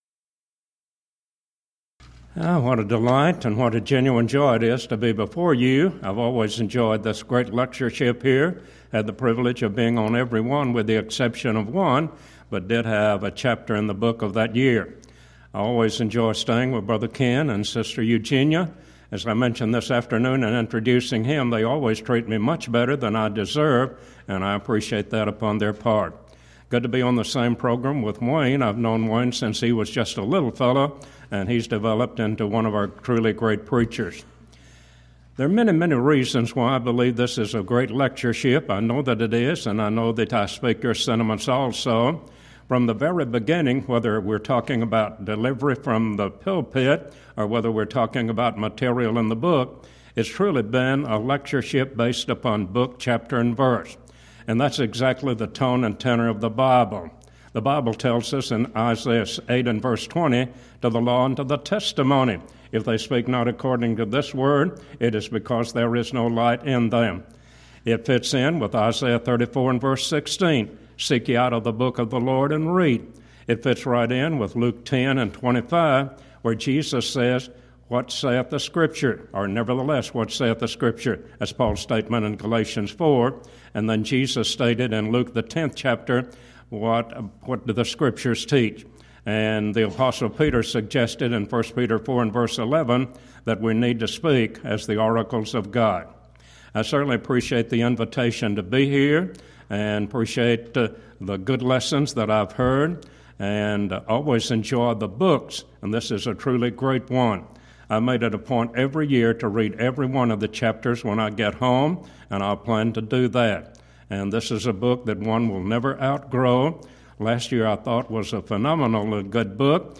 Event: 11th Annual Schertz Lectures